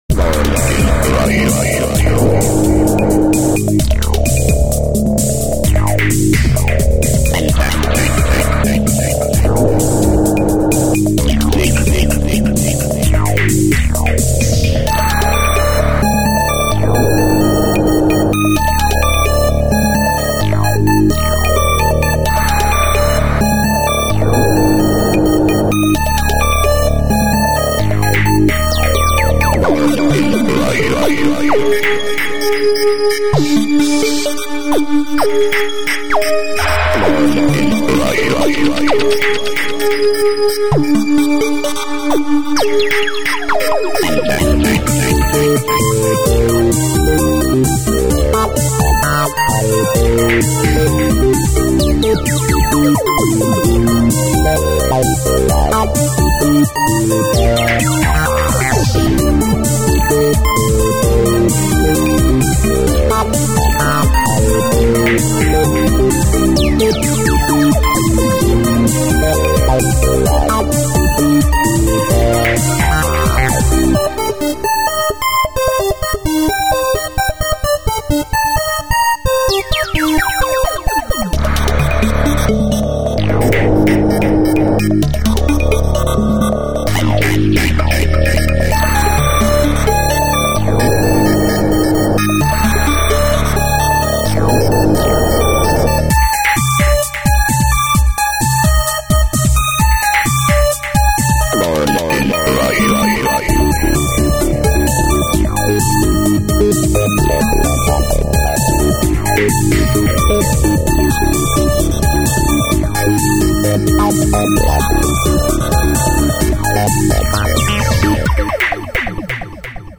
Electronic music